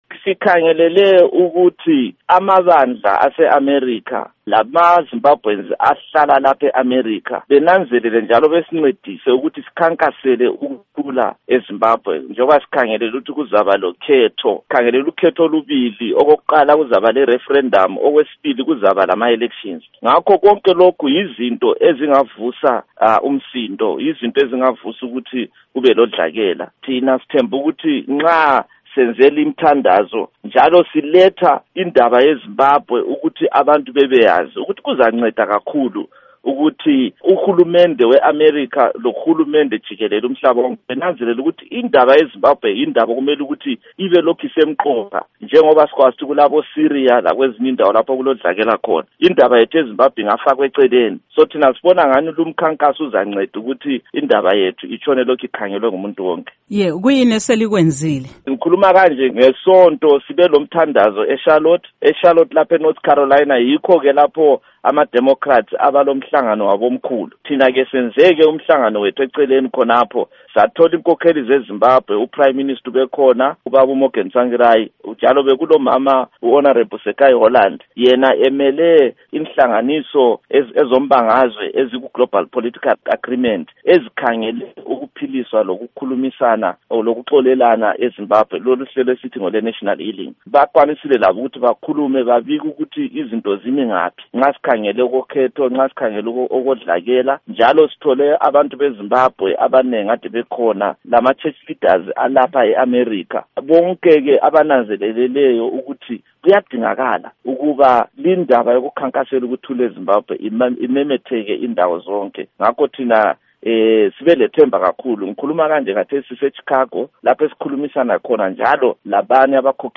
Ingxoxo LoMnu